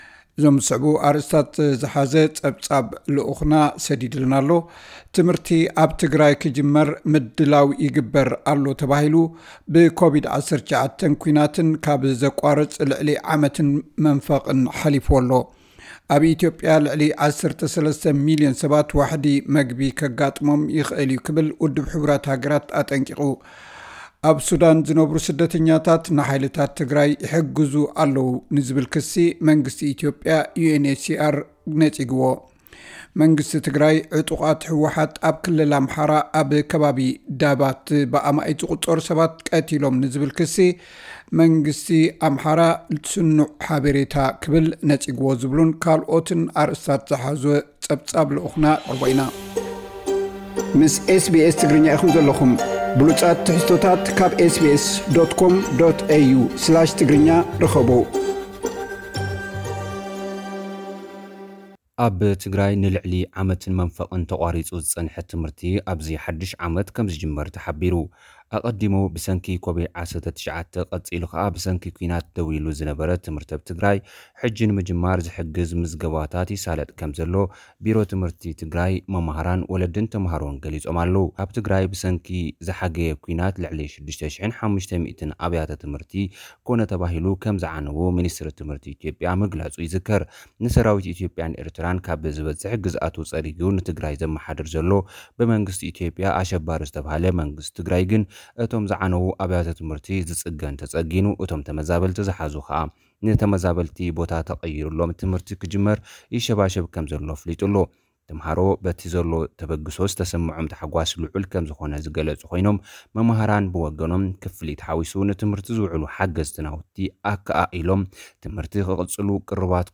ዕጡቓት ህወሓት ልዕሊ 100 ሰባት ኣብ ክልል ኣምሓራ ቀቲሎም ዝብል ክሲ መንግስቲ ክልልት ትግራይ ይነጽጎ። (ጸብጻብ)